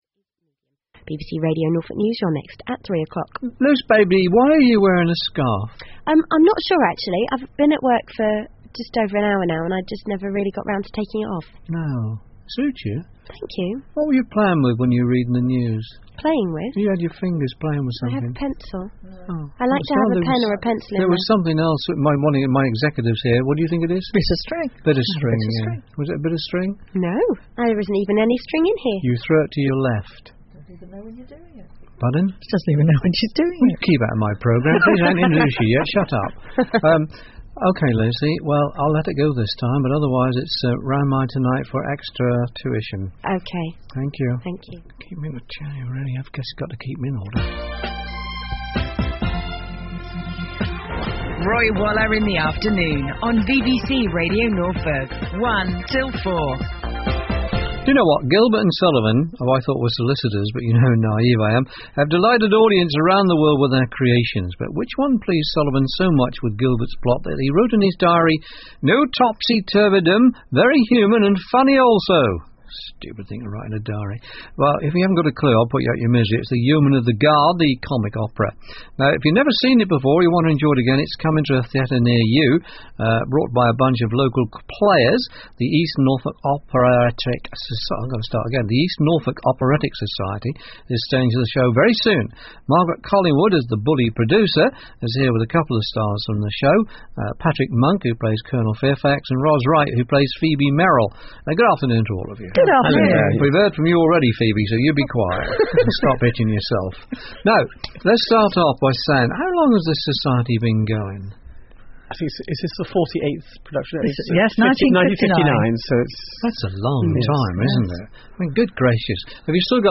Publicity Interview broadcast on BBC Radio Norfolk on 25 April 2007